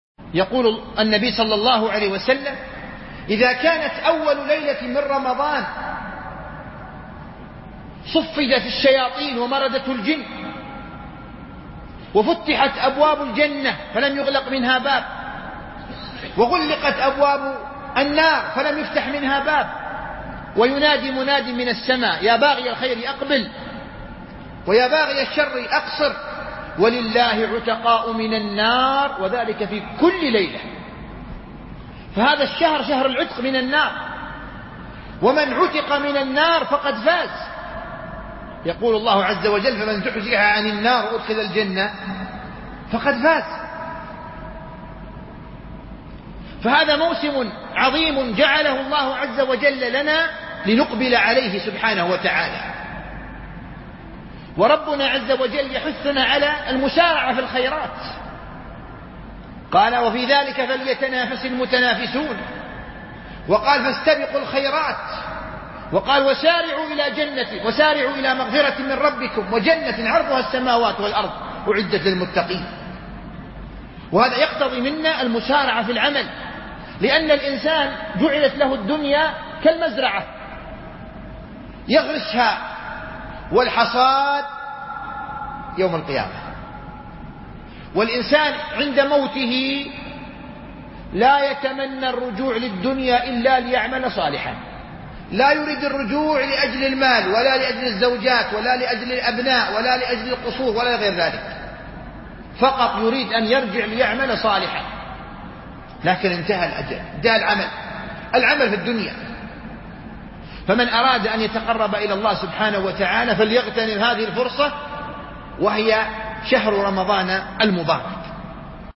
التنسيق: MP3 Stereo 22kHz 32Kbps (VBR)